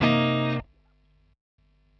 Cm7.wav